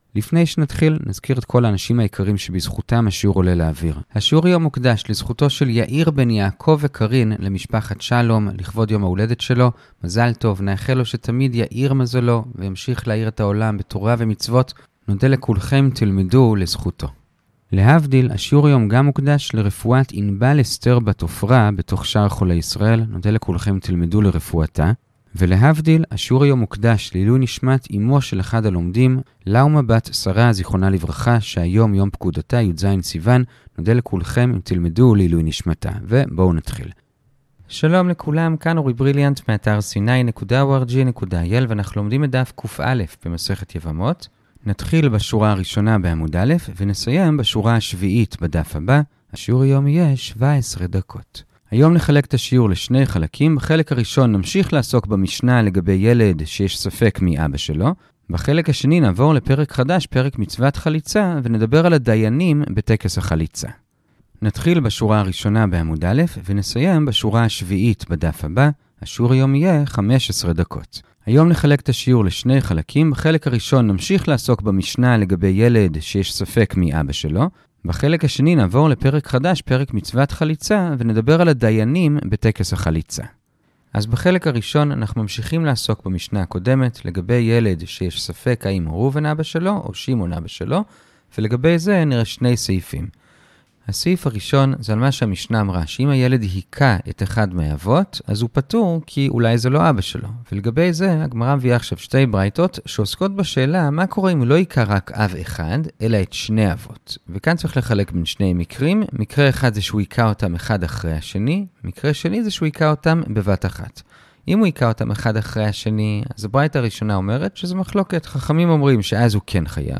הדף היומי - יבמות קא - הדף היומי ב15 דקות - שיעורי דף יומי קצרים בגמרא